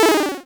Techmino/media/effect/chiptune/finesseError.ogg at 43e2caa30ef40066a5bc223e999d2669e674ead9
finesseError.ogg